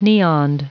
Prononciation du mot neoned en anglais (fichier audio)
Prononciation du mot : neoned